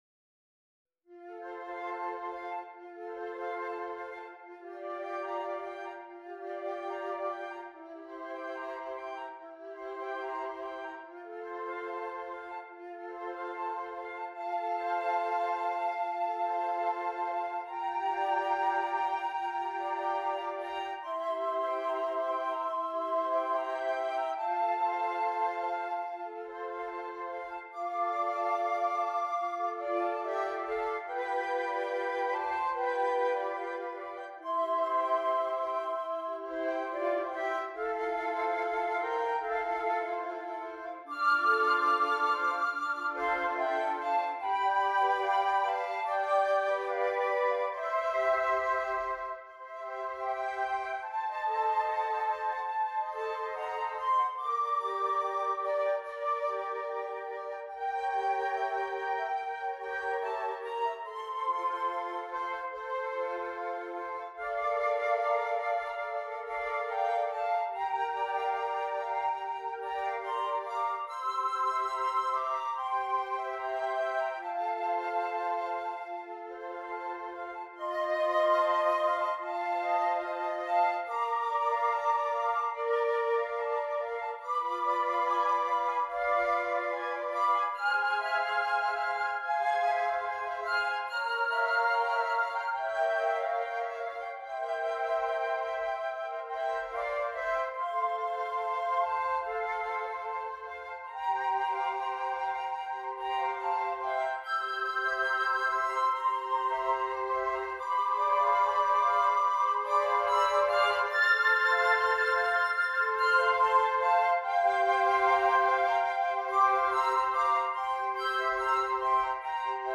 6 Flutes